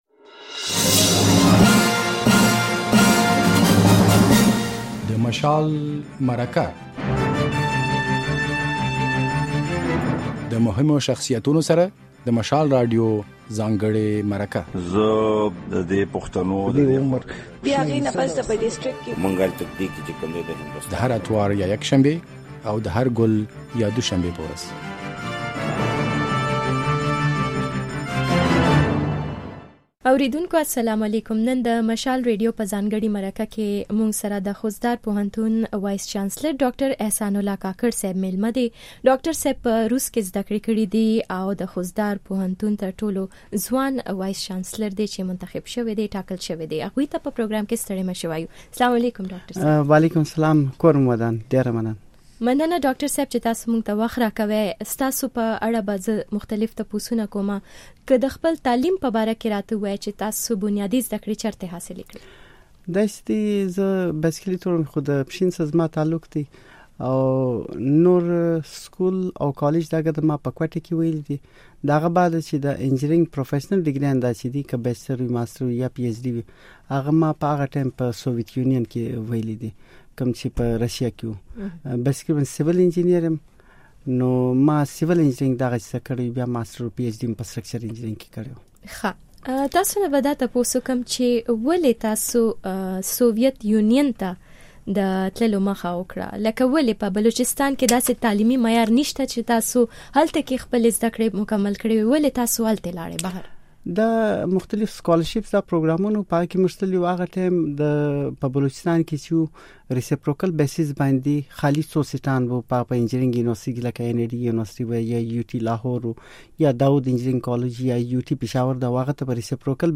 د مشال مرکې پاڼه کې درته هرکلی وایو.